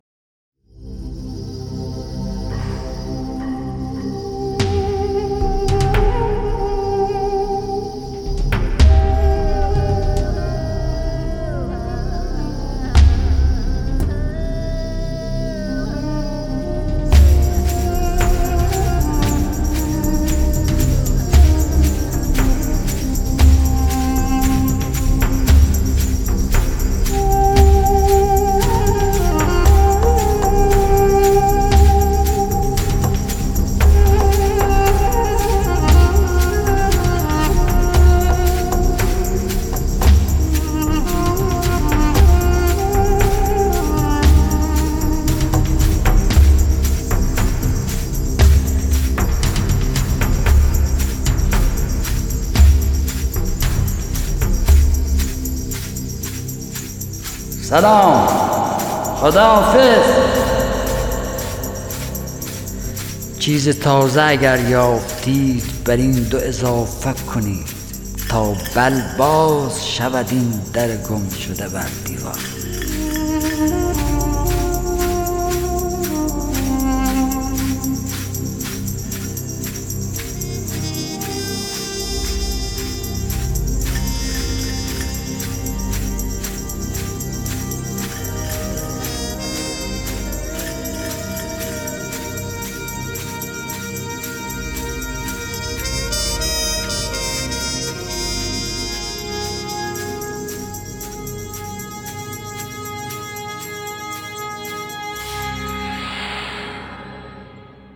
اطلاعات دکلمه
گوینده :   [حسین پناهی]